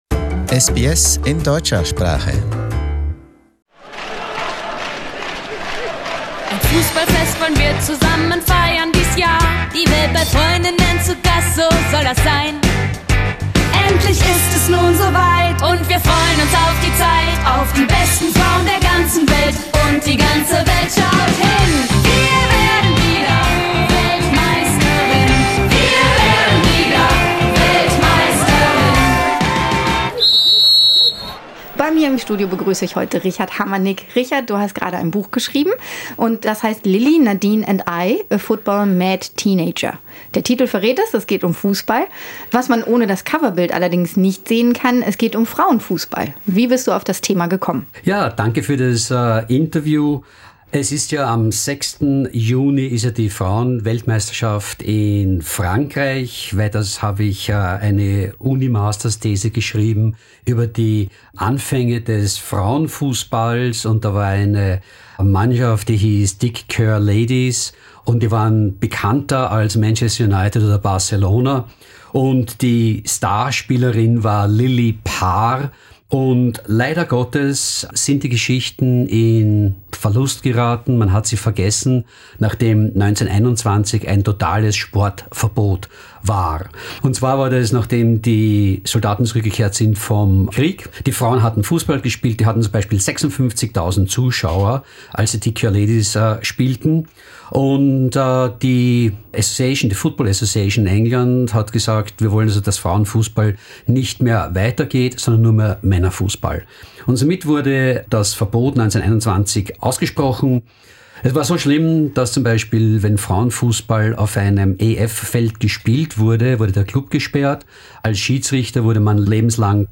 im Brisbane Studio